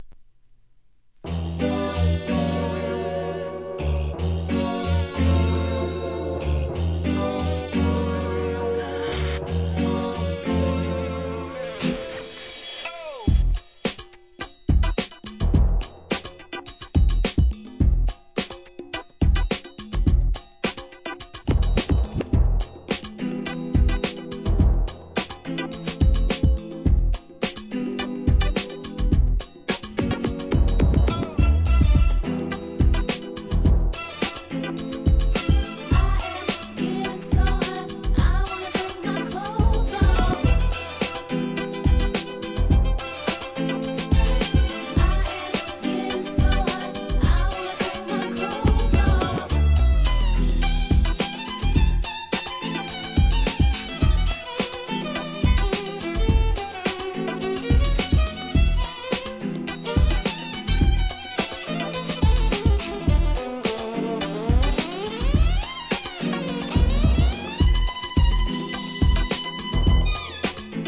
HIP HOPのヒット曲の数々をJAZZカヴァーする企画アルバム